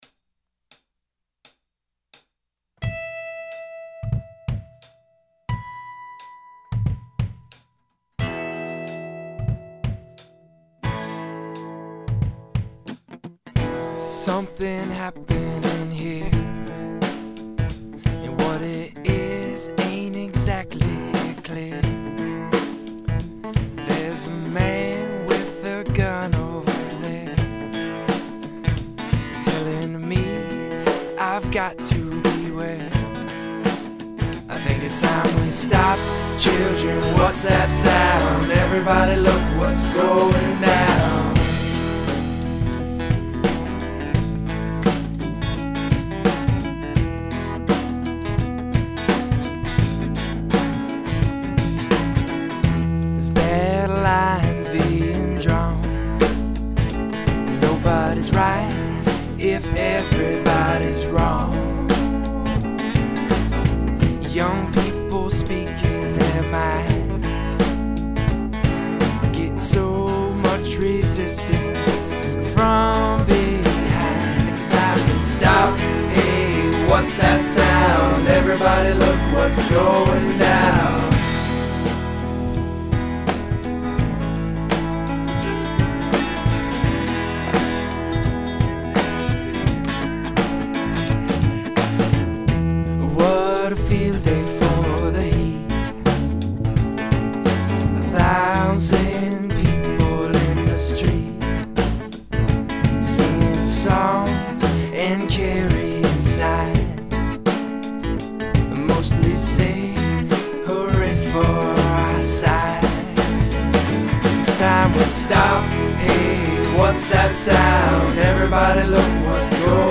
The band was tracked live in LH-1 using a laptop, while the vocals were overdubbed in the control room.